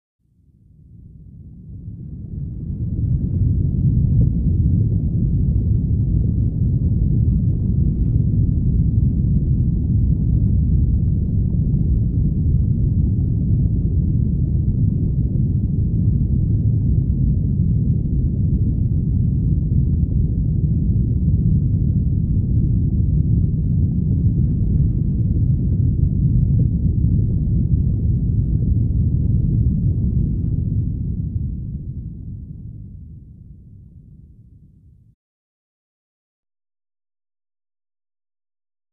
Rumble